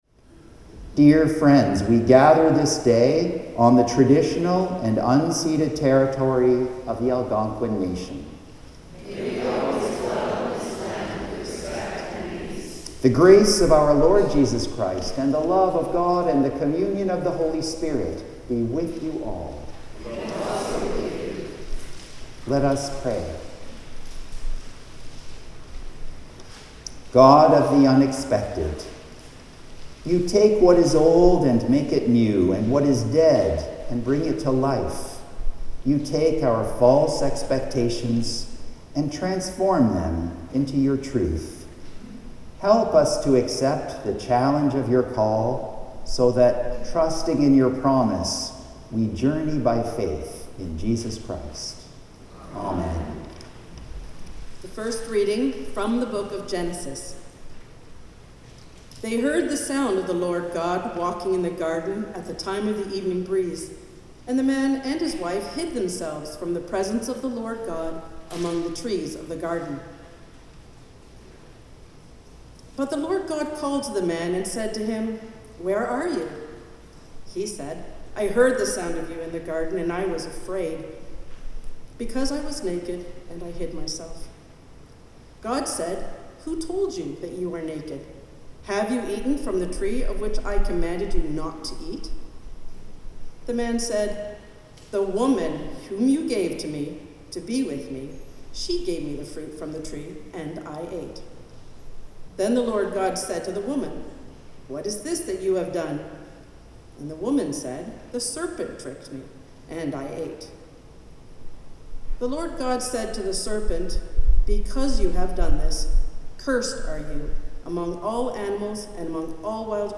THIRD SUNDAY AFTER PENTECOST Land Acknowledgement, Greeting & Collect of the Day First Reading: Genesis 3:8-15 Psalm 130: The Lord shall redeem us from all our sins Second Reading: 2 Corinthians 4:13-5:1 (reading in French) Hymn: Yours the Hand that Made Creation (Common Praise #256 –...